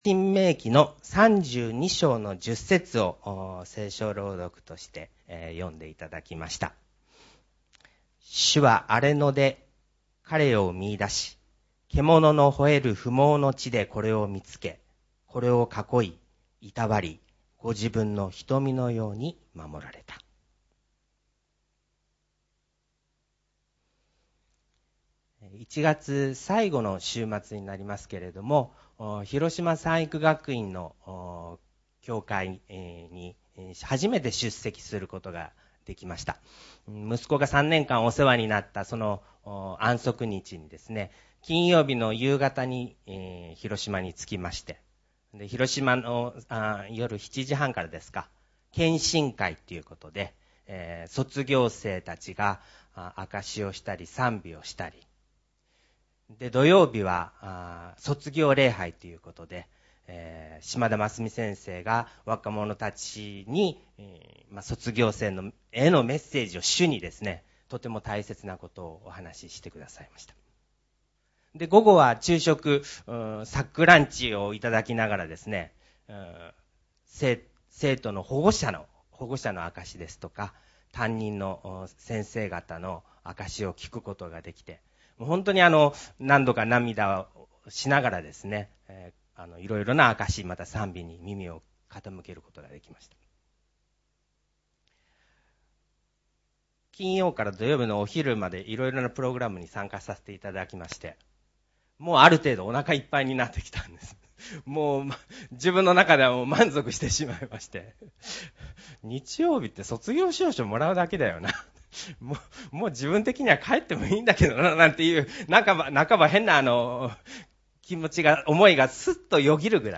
信徒礼拝